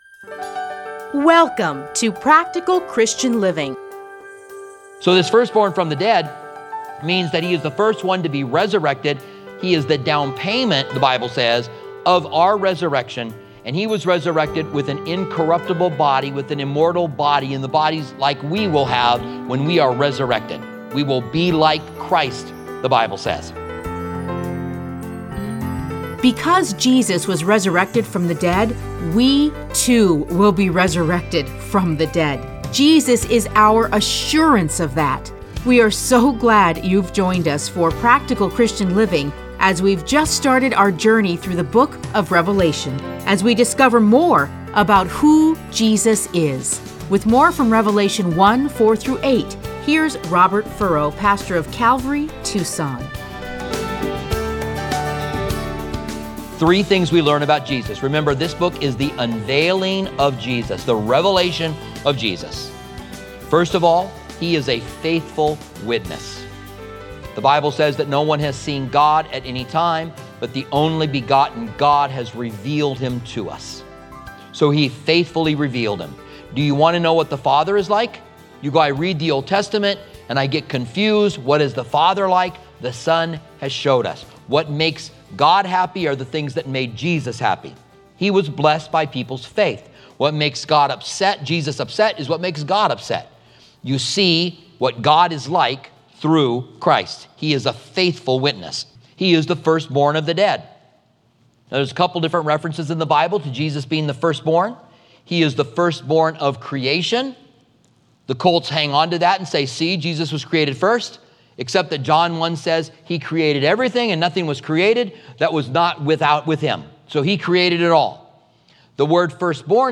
Listen to a teaching from Revelation 1:4-8.